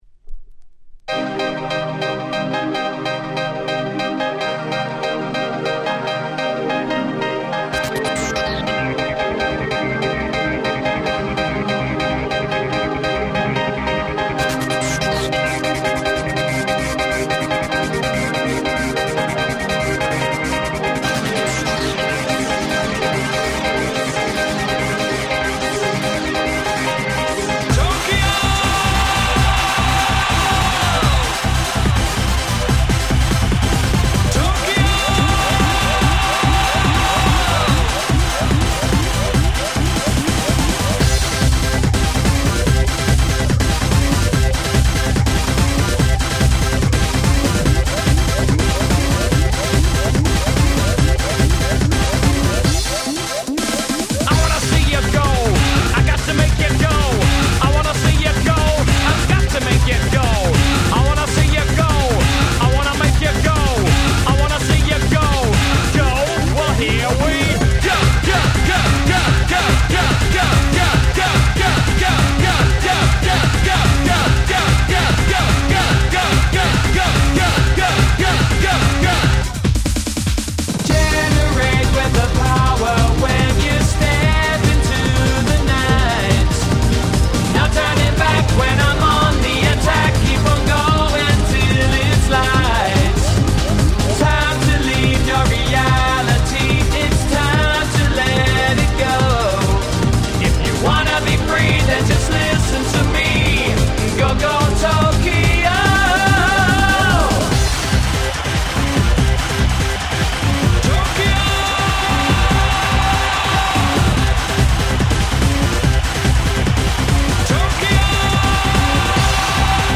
ダンスポップ